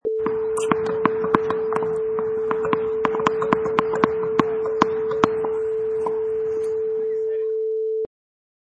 Sound files: Tennis Ball Bounce 1
Tennis ball bounces
Product Info: 48k 24bit Stereo
Category: Sports / Tennis
Try preview above (pink tone added for copyright).
Tennis_Ball_Bounce_1.mp3